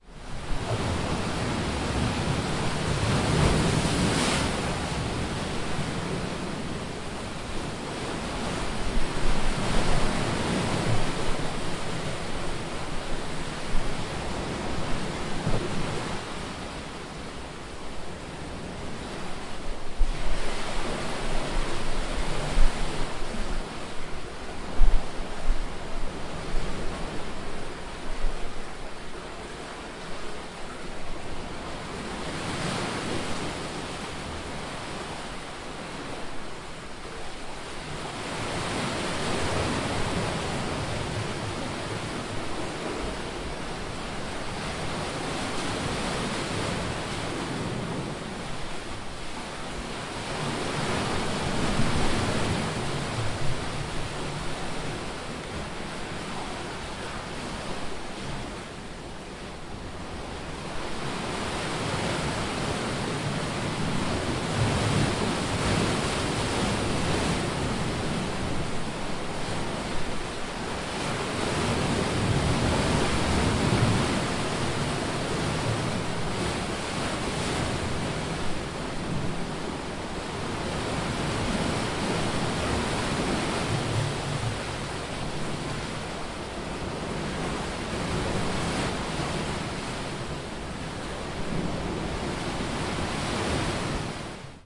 大不列颠 " 岩石内的波浪
我自己用ZOOM H4录制。
Tag: 飞溅 沙滩 海洋 氛围 海浪 海边 飞溅 海岸 沙滩 海边 声景观 自然 现场录音 岩石 夏天